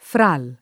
fral [ fral ] e fra ’l [id.] → fra il